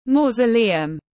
Mausoleum /ˌmɔː.zəˈliː.əm/